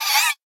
Minecraft Version Minecraft Version latest Latest Release | Latest Snapshot latest / assets / minecraft / sounds / mob / guardian / land_idle1.ogg Compare With Compare With Latest Release | Latest Snapshot
land_idle1.ogg